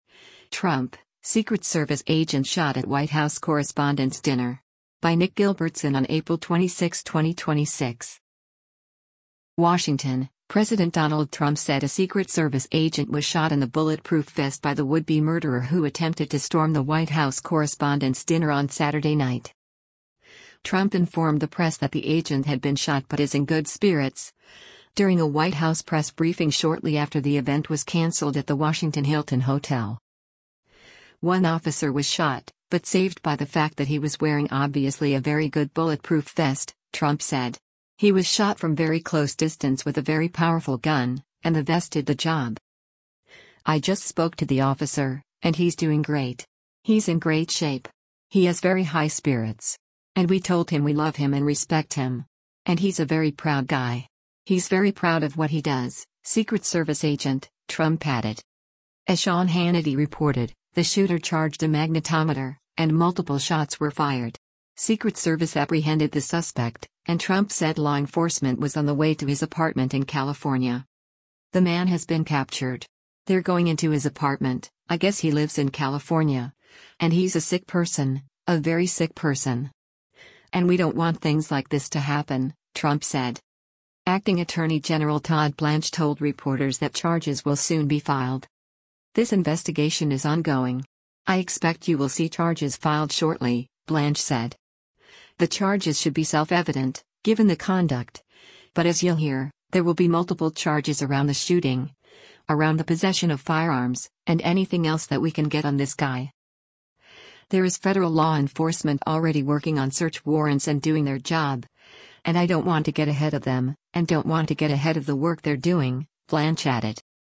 Trump informed the press that the agent had been shot but is in “good spirits,” during a White House press briefing shortly after the event was canceled at the Washington Hilton hotel.